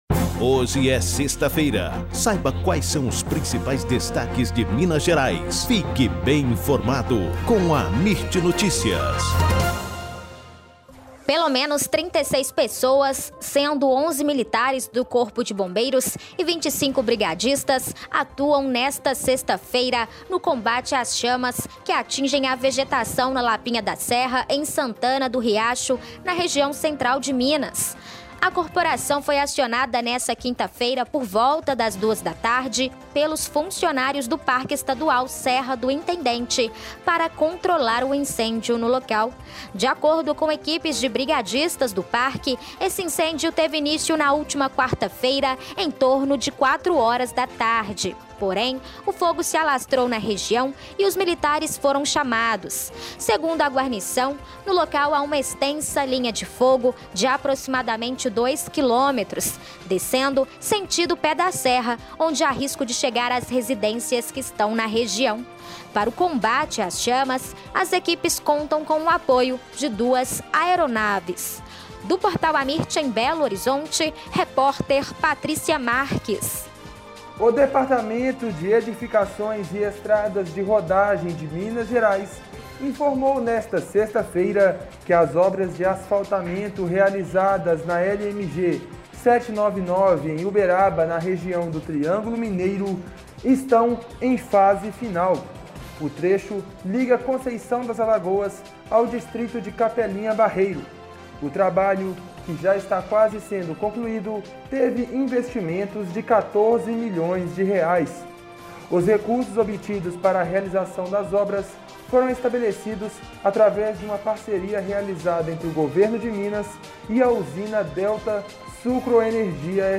AMIRT Amirt Notícias Destaque Notícias em áudio Rádio e TelevisãoThe estimated reading time is less than a minute